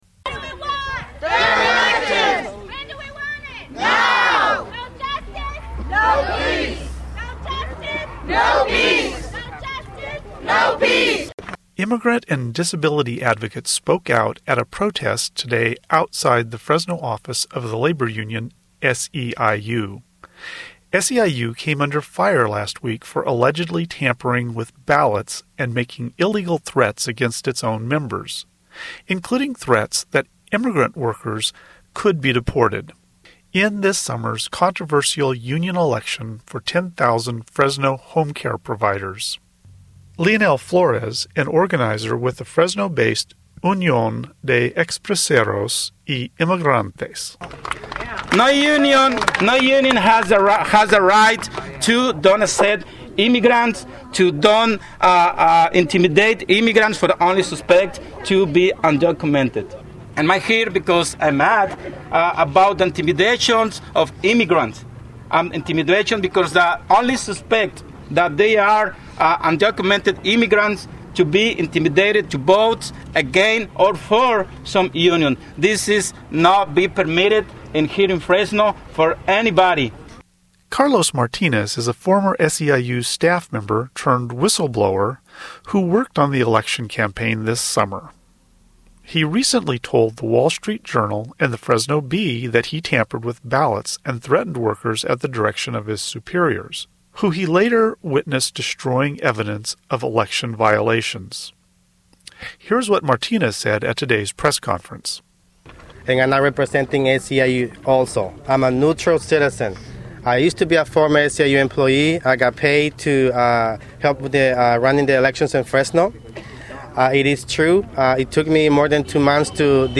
Immigrant and disability advocates spoke out at a protest today outside the Fresno office of the labor union SEIU. SEIU came under fire last week for allegedly tampering with ballots and making illegal threats against its own members—including threats that immigrant workers could be deported—in this summer's controversial union election for 10,000 Fresno homecare providers.